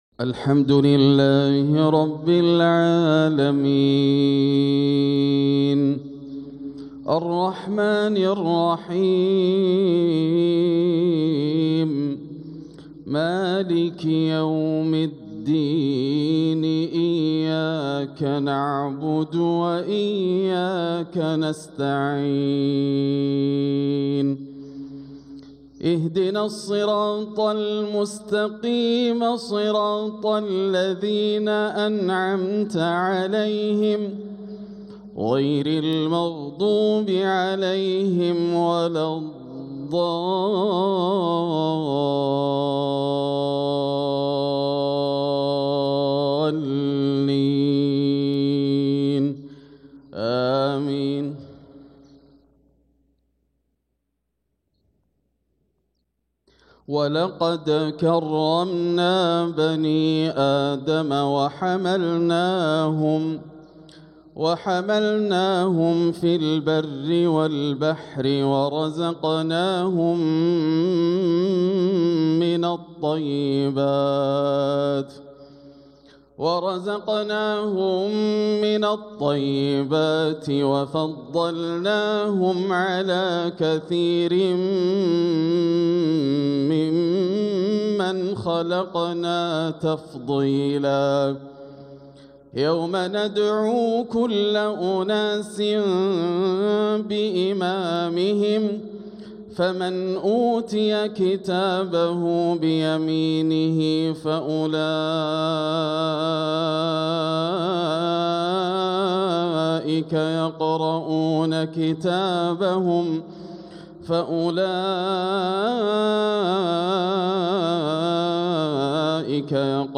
صلاة الفجر للقارئ ياسر الدوسري 13 صفر 1446 هـ
تِلَاوَات الْحَرَمَيْن .